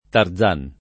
Tarzan [t#rzan; meno com.